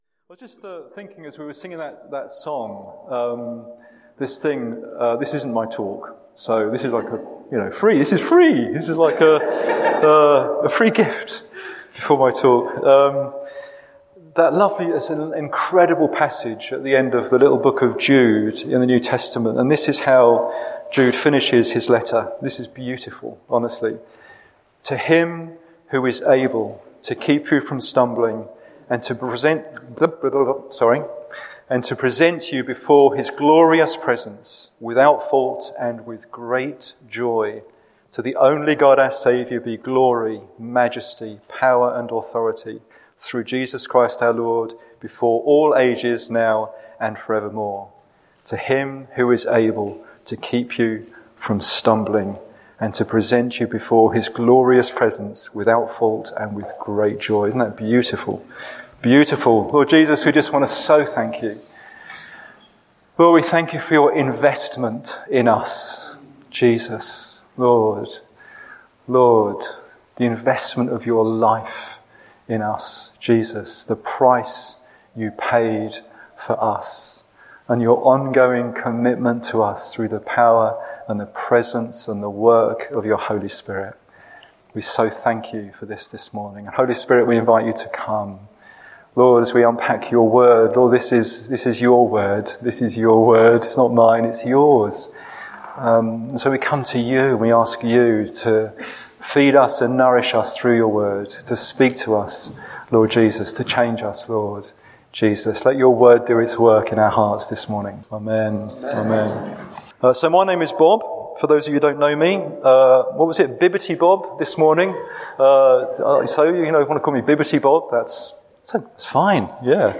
The Church in Bassett Street Podcast Archive